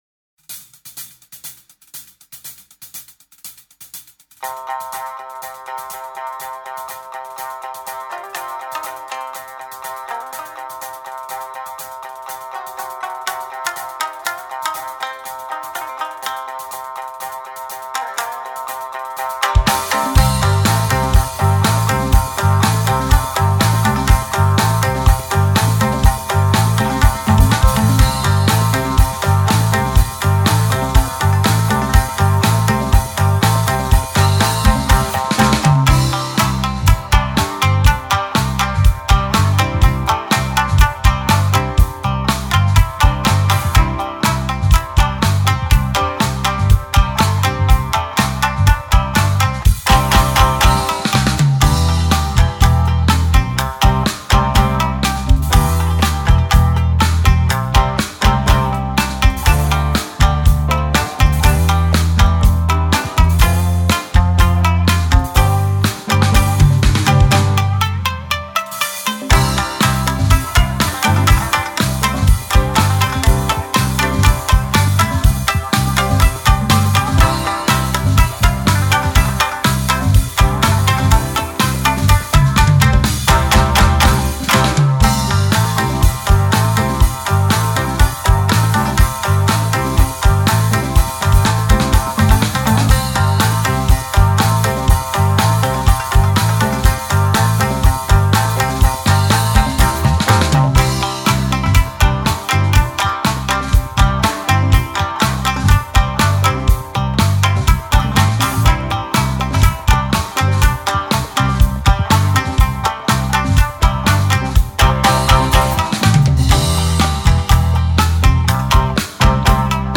Let's do a full play through of our original song of Funny Street!